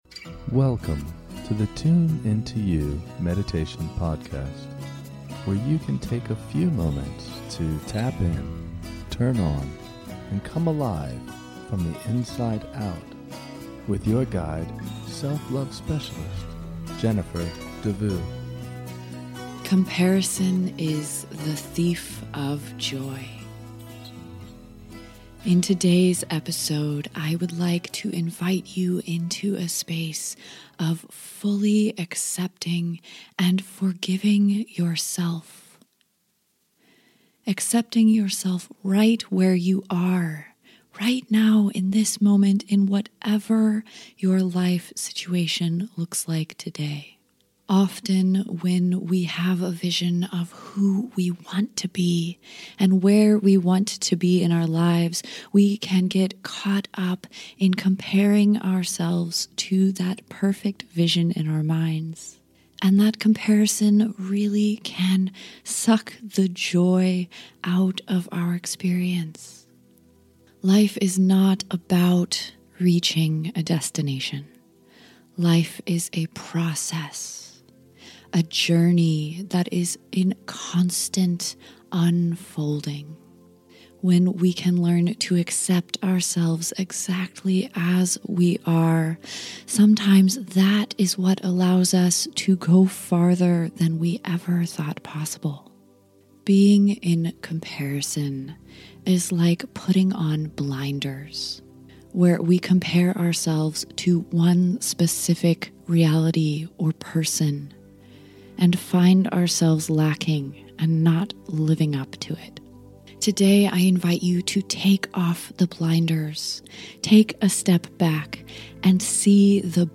This short guided meditation is a reminder for all of us who spend a little too much time comparing, judging, and criticizing ourselves. In this short guided meditation, you can begin to let your comparisons go by taking a step back and seeing a bigger picture.